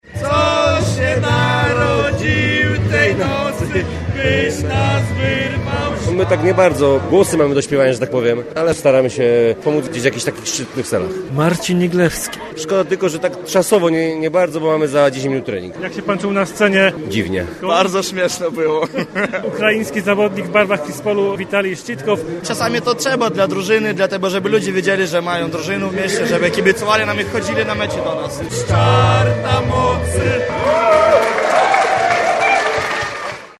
Charytatywne śpiewanie kolęd odbyło się w największej we Wrześni galerii handlowej.
io7fdrx984izt2l_jak-siatkarze-spiewali-koledy.mp3